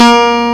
Index of /m8-backup/M8/Samples/Fairlight CMI/IIX/PLUCKED
BAZOUKI.WAV